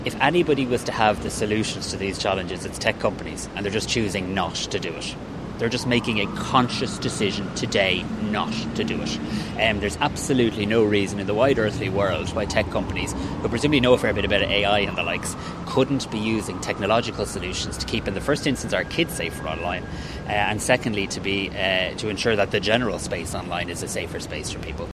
The Tánaiste also says the onus for safer online content ultimately lands with the social media companies: